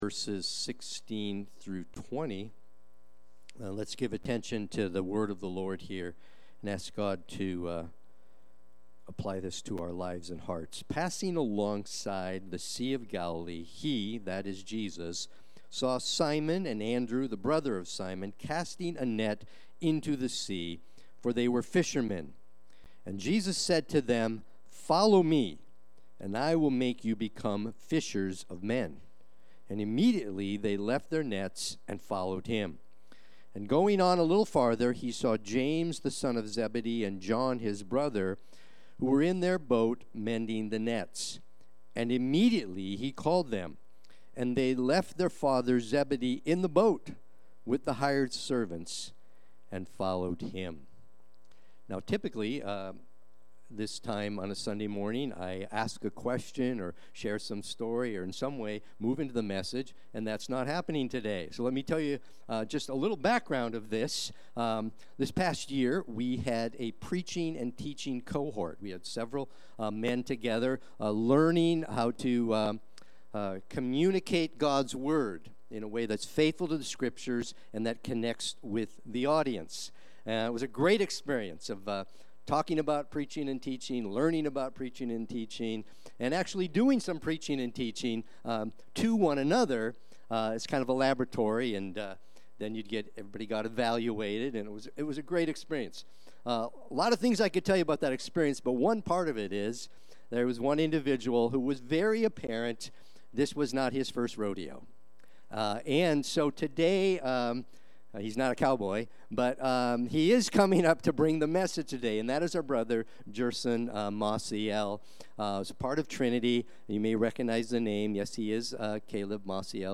Watch the replay or listen to the sermon.
Sunday-Worship-main-9.28.25.mp3